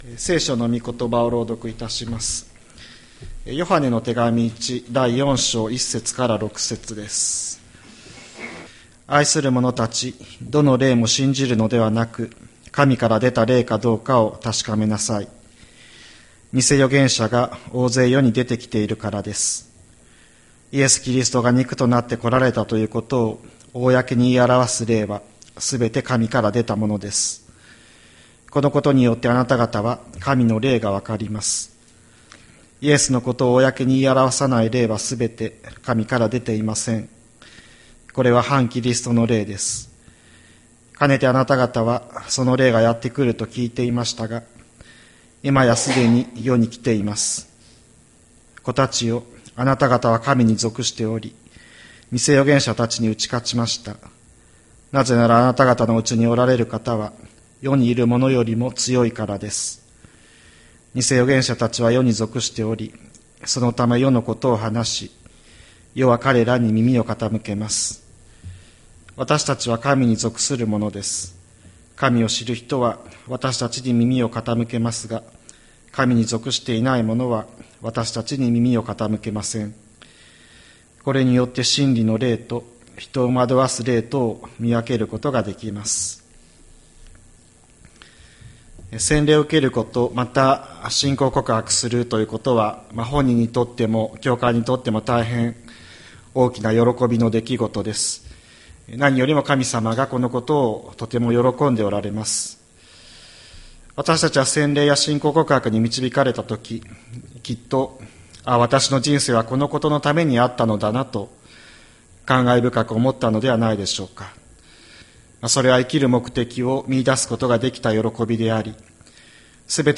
2026年01月18日朝の礼拝「見極めるべきこと」吹田市千里山のキリスト教会
千里山教会 2026年01月18日の礼拝メッセージ。